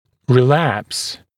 [rɪ’læps][ри’лэпс]рецидив; рецидивировать; возвращаться к первоначальному положению